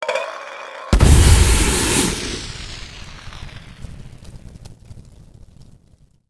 smokegrenade.ogg